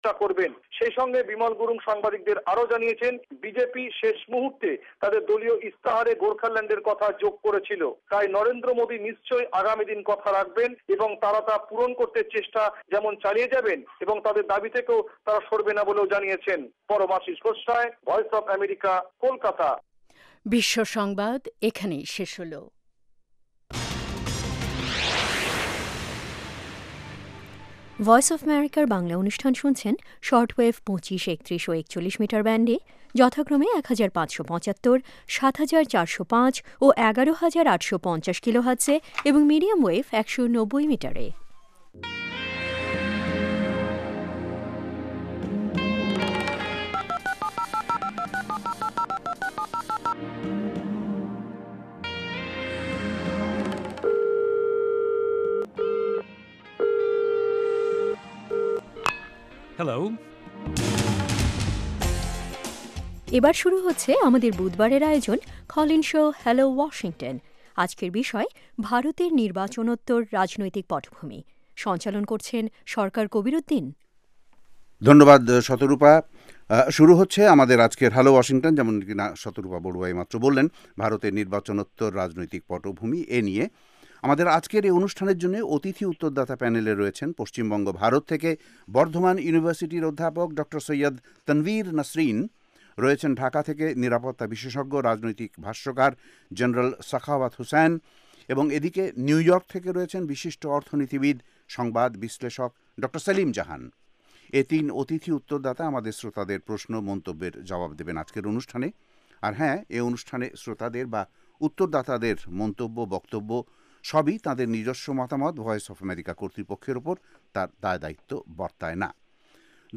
Hello Washington is VOA Bangla's flagship call-in show, providing in-depth analysis of major social and political issues in the United States and Bangladesh.